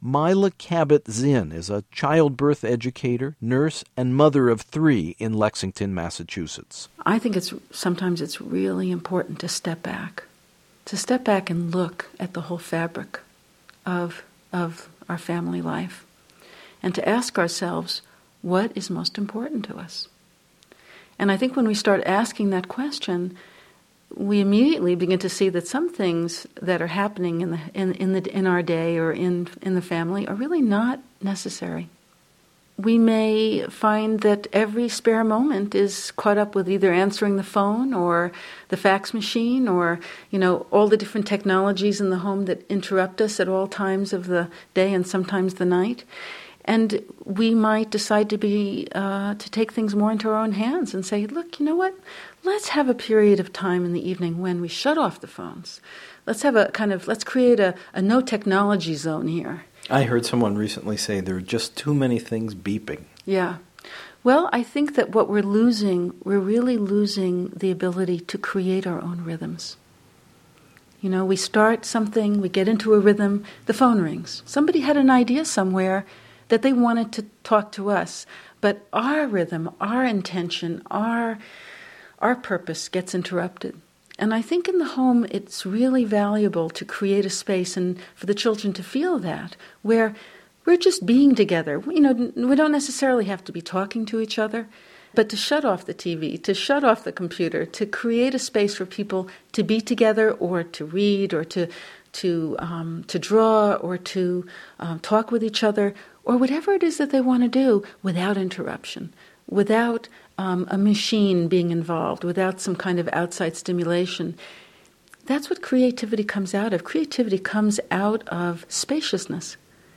How to inspire wise choices in your children, create spaciousness in the clutter of the modern world, and foster a creative spirit that will shepherd your child through difficult periods are just some of the topics this vocal and dynamic couple explore.